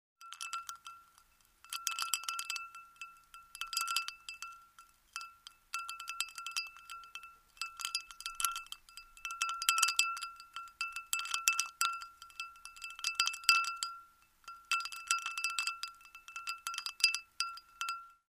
Звуки ресторана
Смешивают жидкость со льдом в стакане